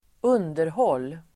Uttal: [²'un:derhål:]